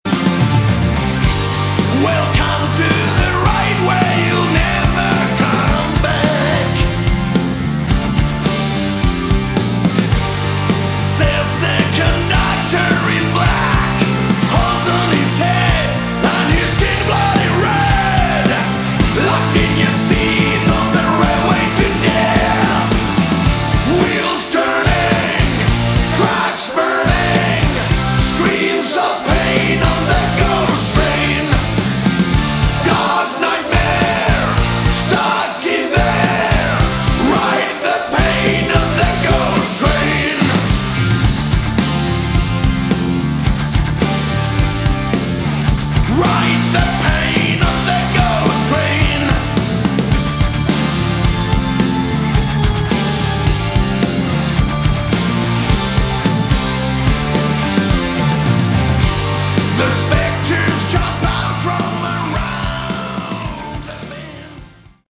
Category: Shock Rock/Horror Rock